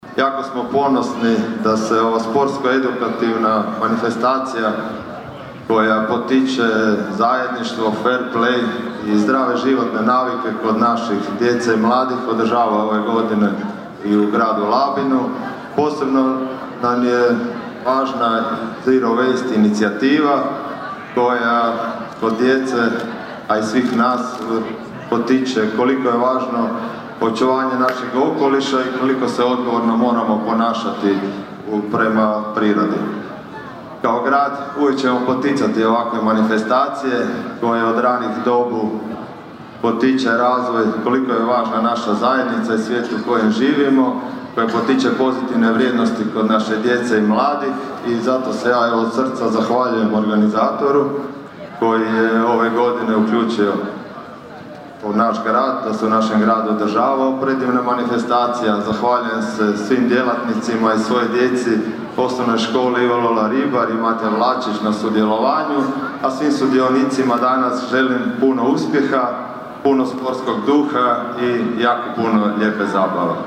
Pozdravi i svečano paljenje plamena
Djeci i njihovim učiteljima obratio se labinski gradonačelnik Donald Blašković: (